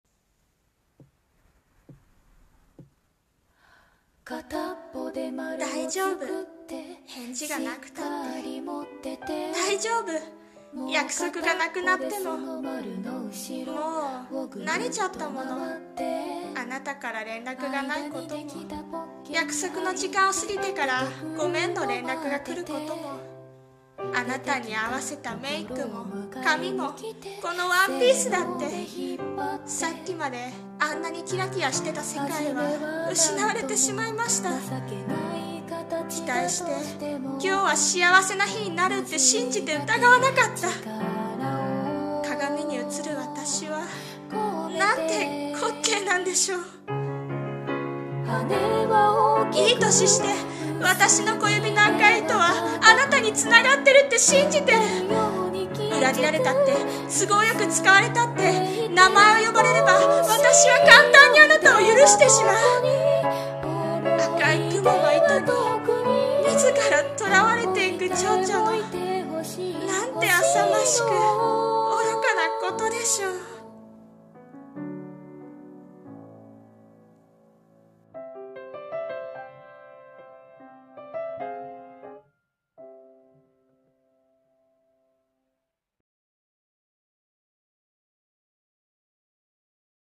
さんの投稿した曲一覧 を表示 声劇【亡骸】